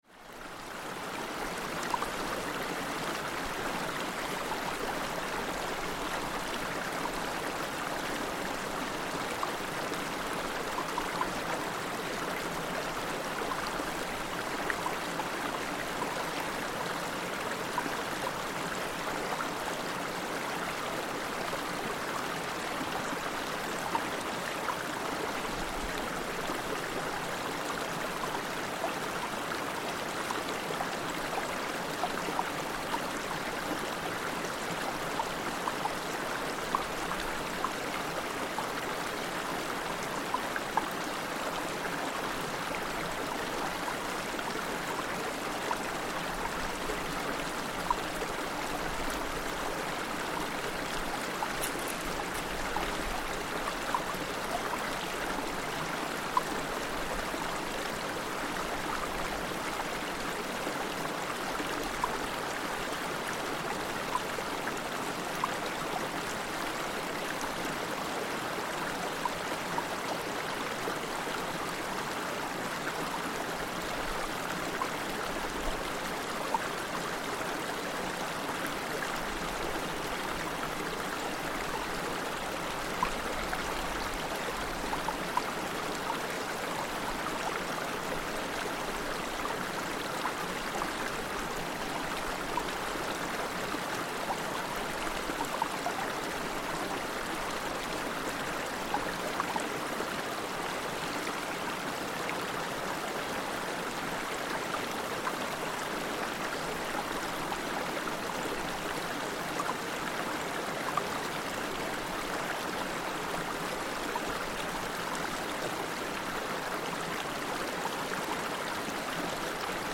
Mountain stream, Monte Civetta
A mountain stream close to Torre Trieste, part of the Monte Civetta group in the Dolomites, Italy. Recorded on a long hike around the complete circuit of Monte Civetta, at 8.30am in the still-fresh morning air at the beginning of a hot August day.
Mountain soundscapes are surprisingly silent, with occasional bird or animal calls and a slight wind the other main sounds to be heard other than the gentle trickling of water down the mountainside.